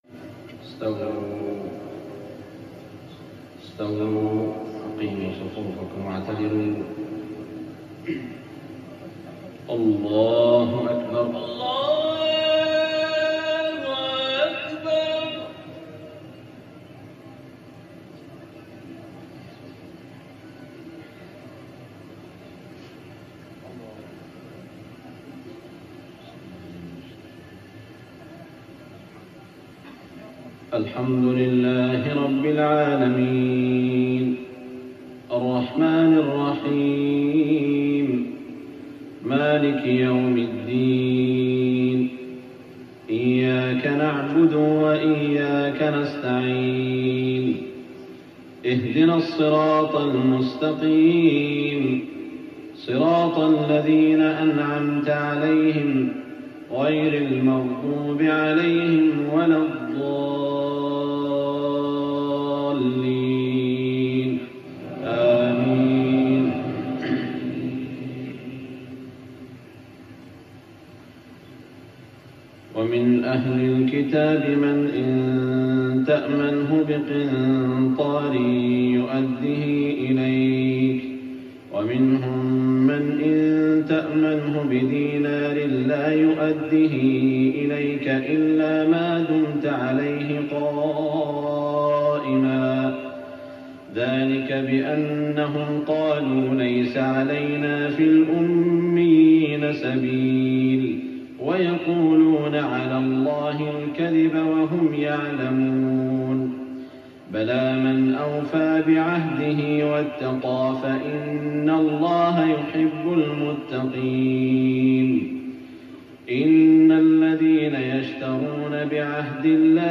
صلاة العشاء 1415هـ من سورة ال عمران 75-80 > 1415 🕋 > الفروض - تلاوات الحرمين